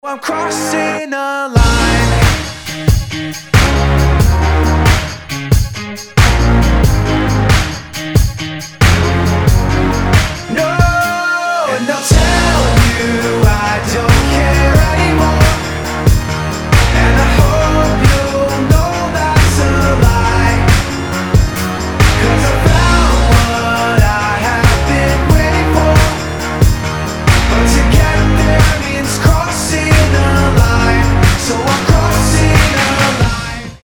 • Качество: 320, Stereo
Electronic
alternative